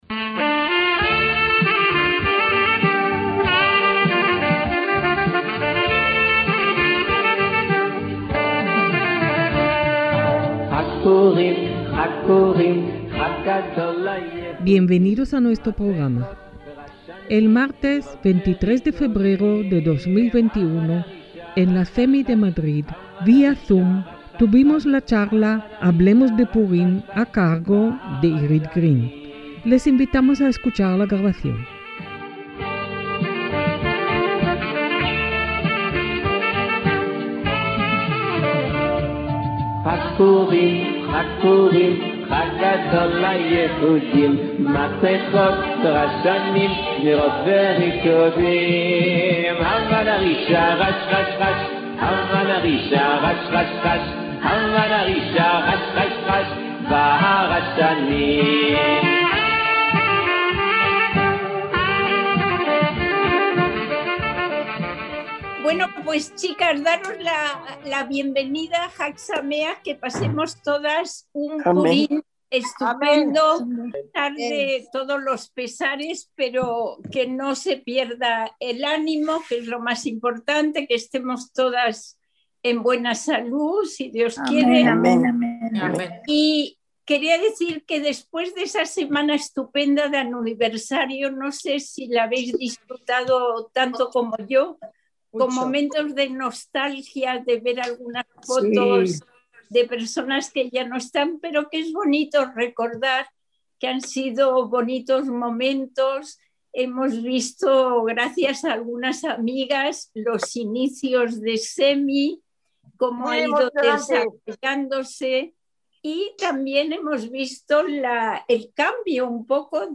Celebrando Purim en reunión virtual, pero disfrazadas (online, CEMI, 23/2/2021)